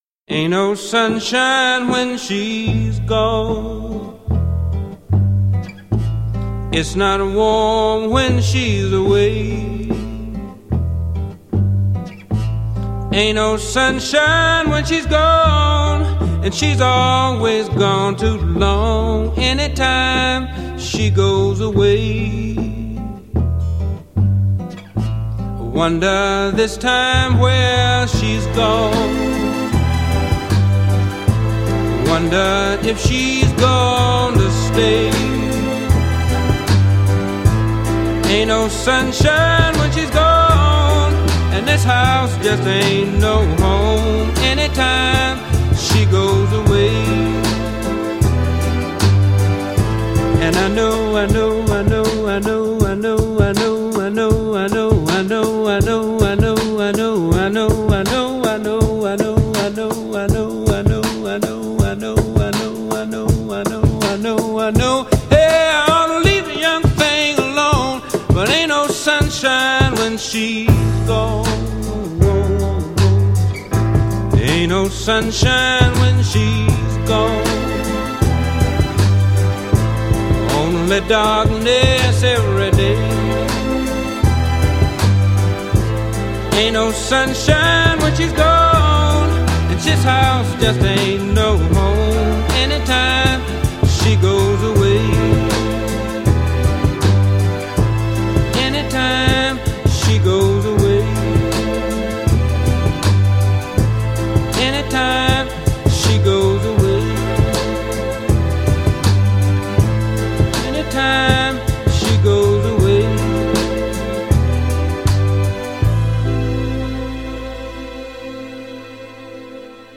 + the singing has beautiful echo on it.
.. and there is a lot of hiss.